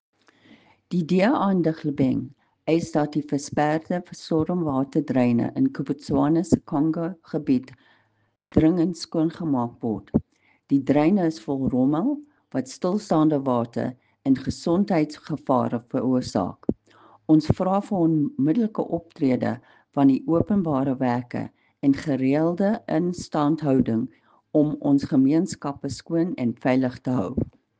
Afrikaans soundbites by Cllr Irene Rügheimer and Sesotho soundbite by Cllr Kabelo Moreeng.